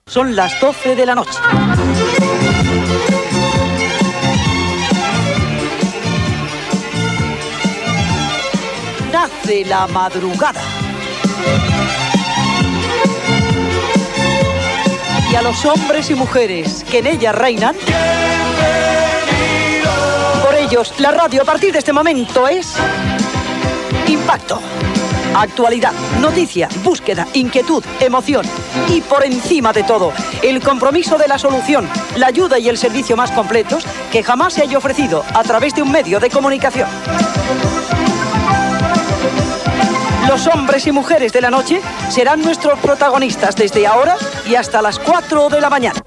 Hora i salutació inicial.
Programa de participació telefònica i companyia per a la gent que treballa de nit.